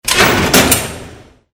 DoorOpen2.wav